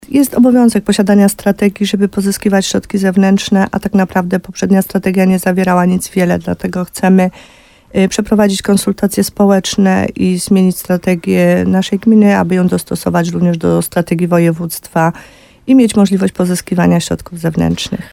W programie Słowo za Słowo na antenie RDN Nowy Sącz wójt gminy Podegrodzie Małgorzata Gromala podkreślała, że zmiana jest konieczna, aby starać się o zewnętrzne dofinansowania do inwestycji.